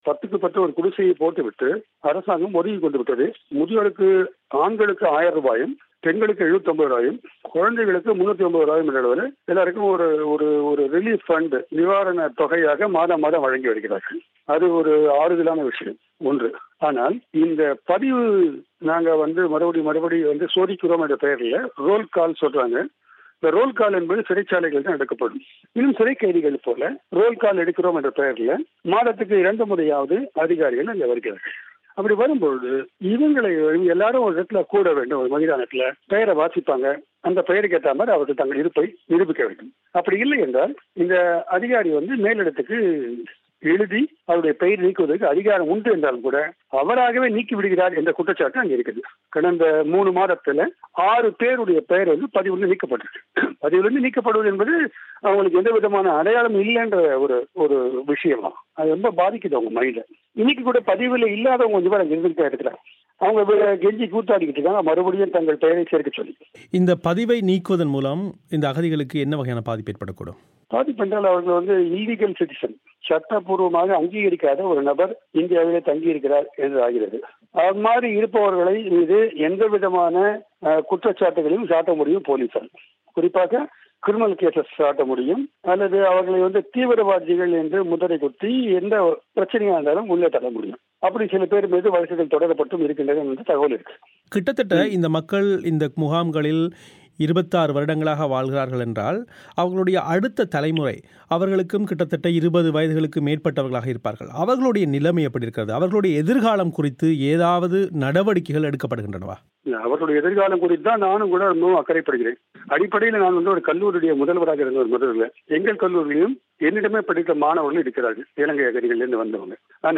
பேட்டியை